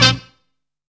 CUTTIN HIT.wav